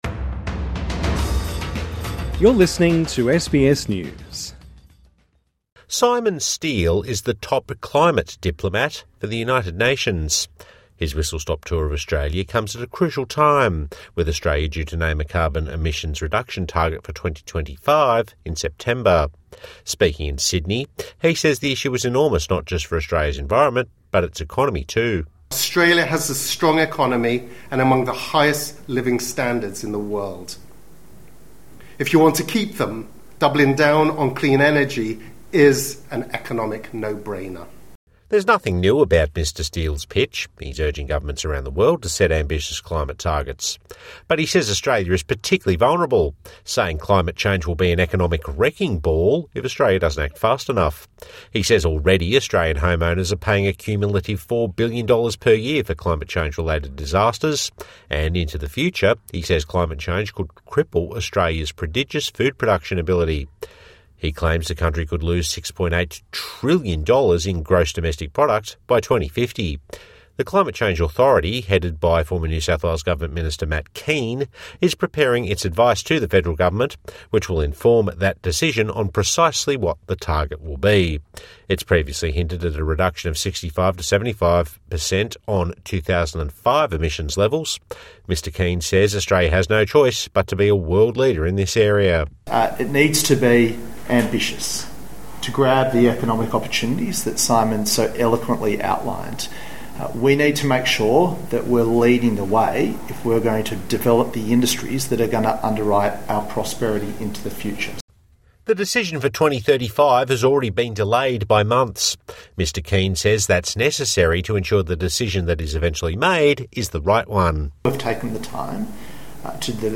UN climate chief Simon Stiell speaking in Sydney Source: SBS News